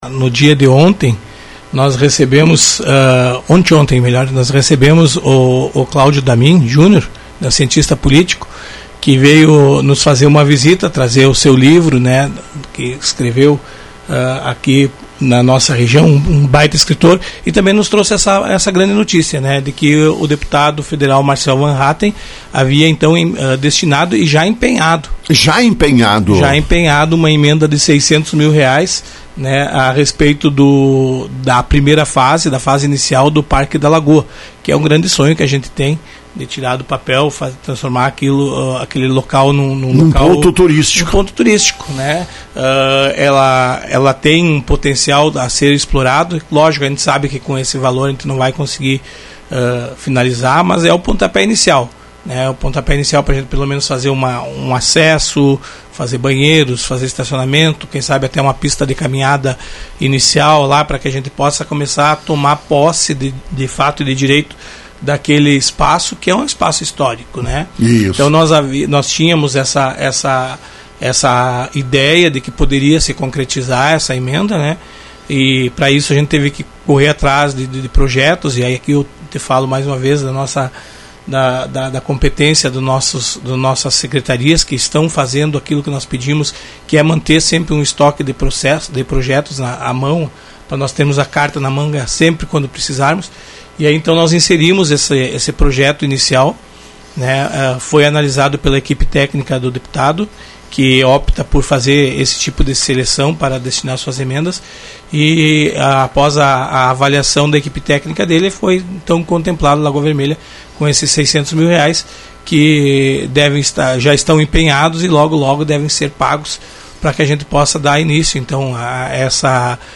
Esses recursos são para serem aplicados no início de uma infraestrutura na área da “Lagoa” que deu origem ao nome do município. Prefeito Eloir Morona informa o que se pretende fazer.